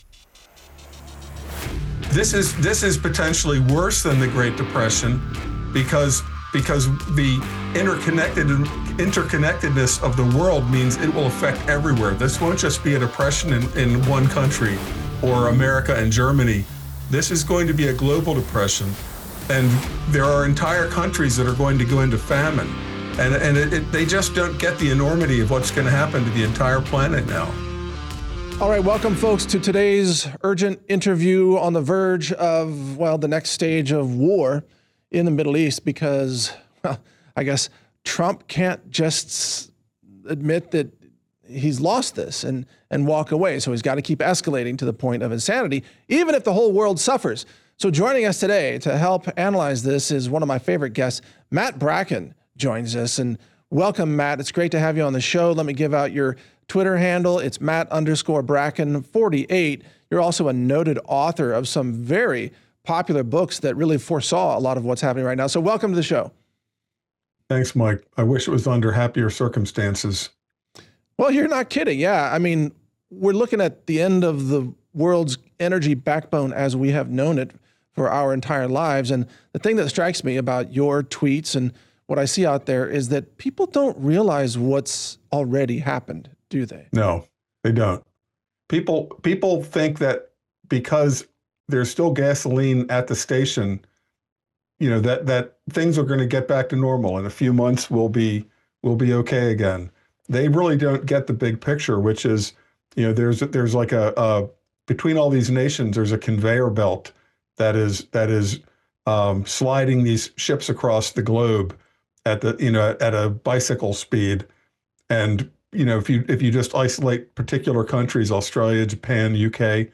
Interview: Global Energy Collapse and the Coming Economic Shock - Natural News Radio